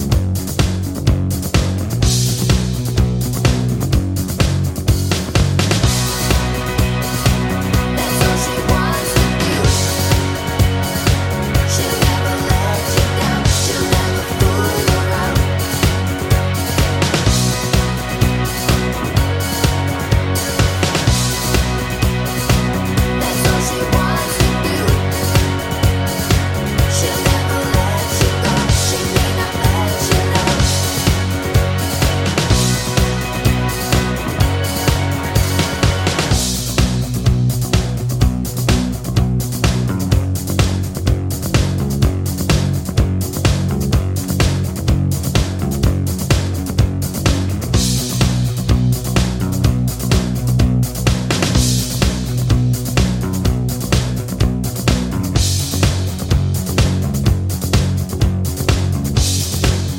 no Backing Vocals Glam Rock 3:38 Buy £1.50